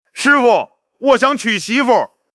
Fish Audio 声音克隆
• 支持情感控制 Emotion Control